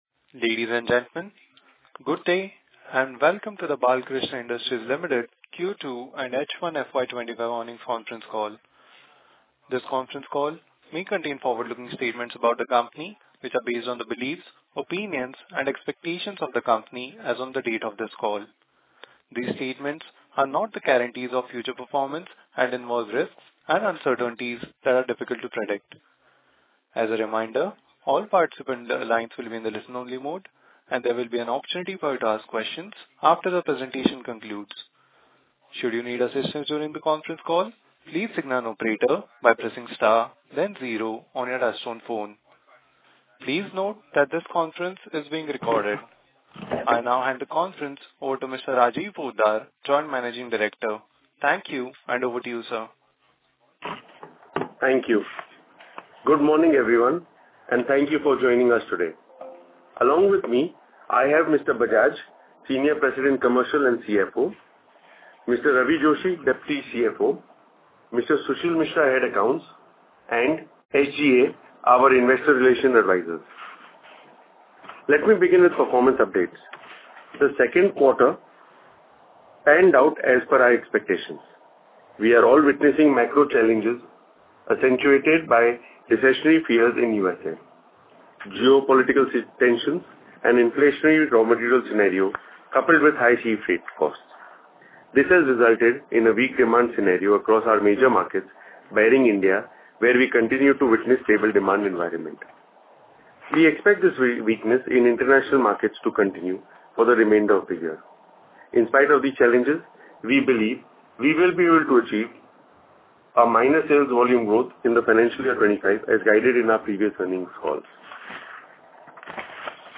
Audio recordings of conference Call dated October 26th, 2024